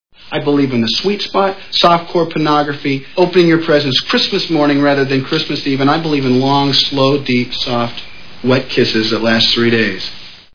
Bull Durham Movie Sound Bites